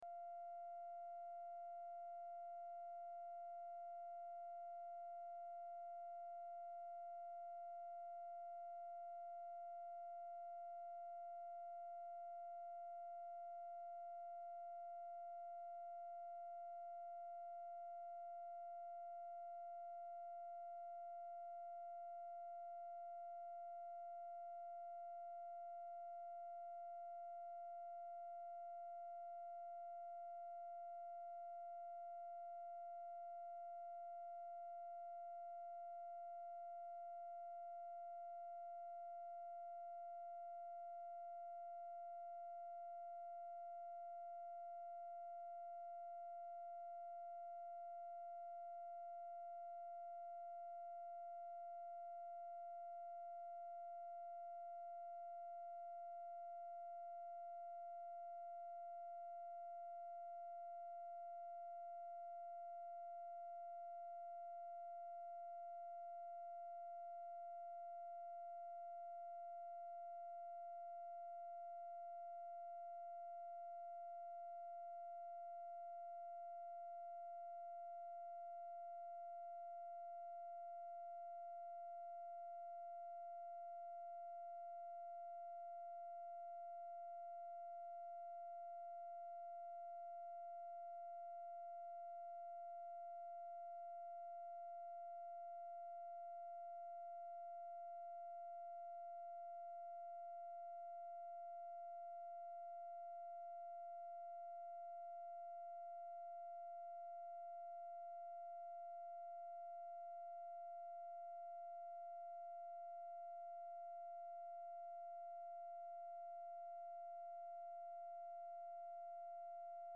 Telephone conversation # 7783, sound recording, BILL MOYERS and J. EDGAR HOOVER, 5/19/1965, 3:00PM
Format Dictation belt